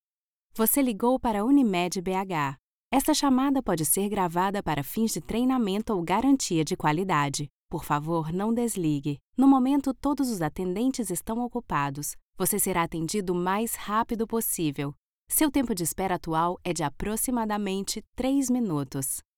Portuguese (Brazil)
Commercial, Young, Soft
Telephony